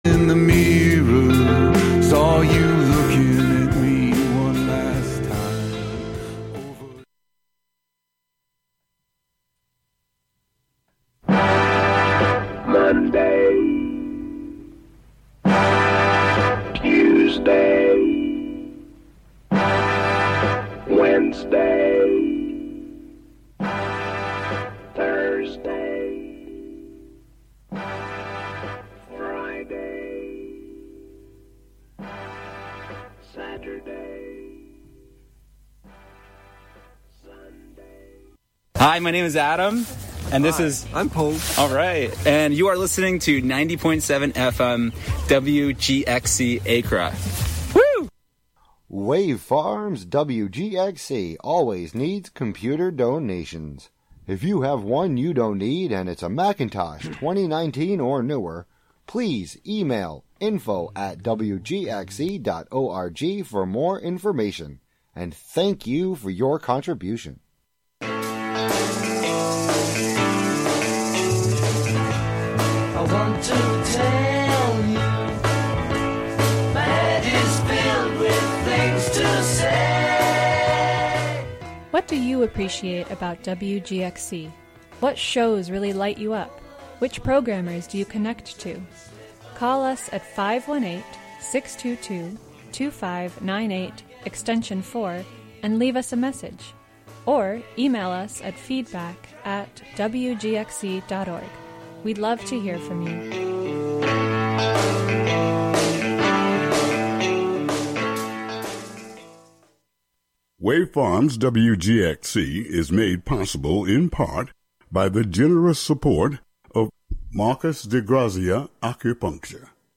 Catskill studio